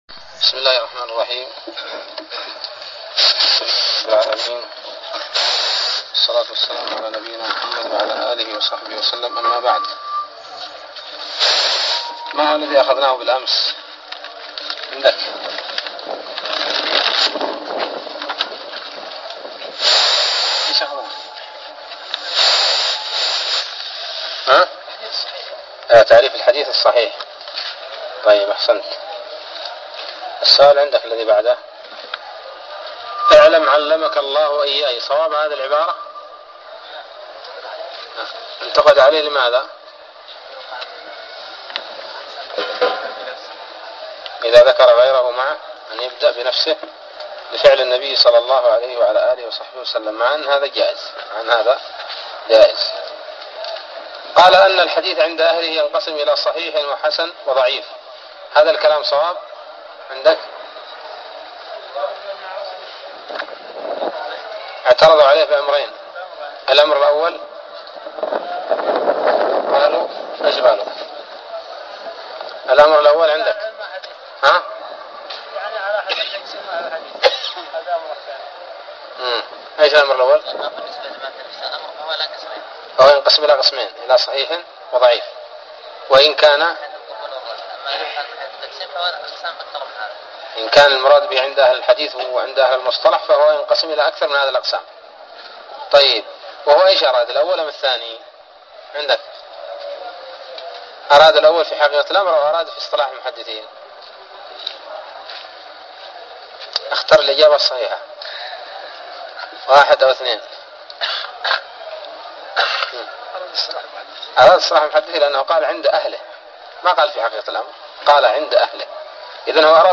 الدرس الثالث من الباعث الحثيث